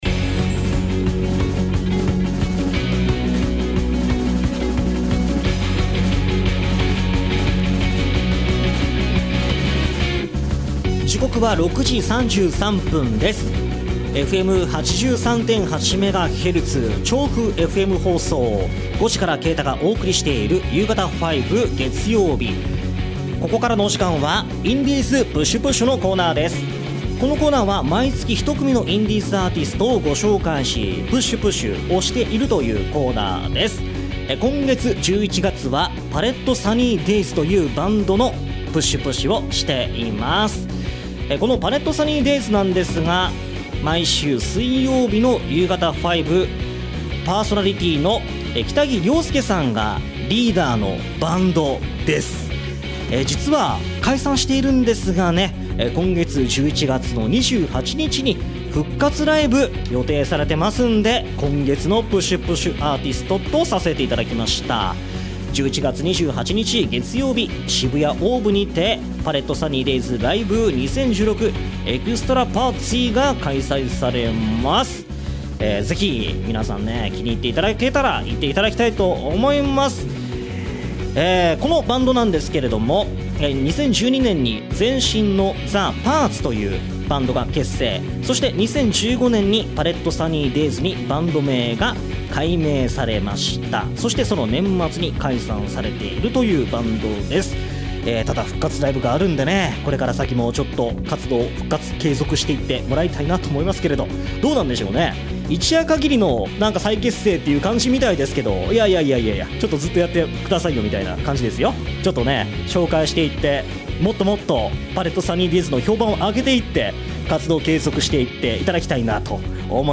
今回の放送同録音源はこちら↓
とにかく、明るく、前向きなポップロックバンドですね！！！